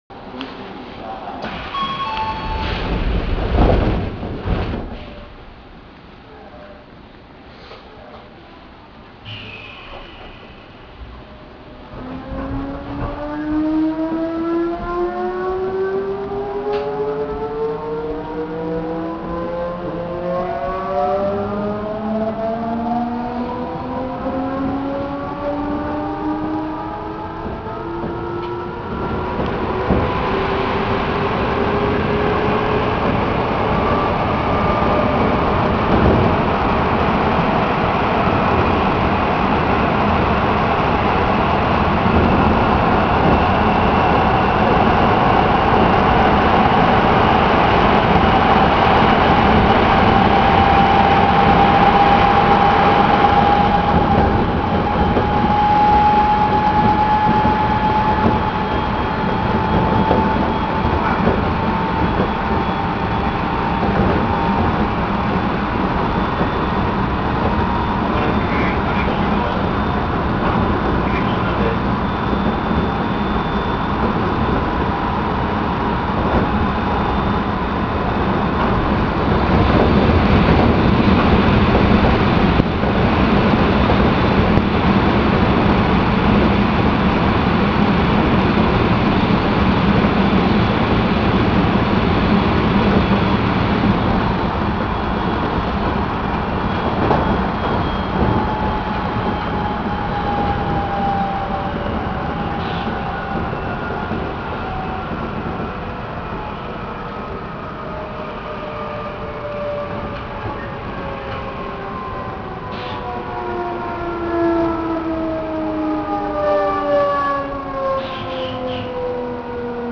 ・1000形走行音
【多摩線】小田急永山→はるひ野（2分19秒：756KB）
とても野太い音。初期の三菱GTOインバータをソフト変更したもので、他の鉄道車両では新京成8800形くらいでしか聞けない音になります（尚、ソフト変更前も新京成8800形と同じ音でした）。
自動放送とドアチャイムは編成によってあったりなかったりします。